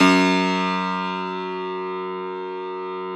53f-pno04-F0.aif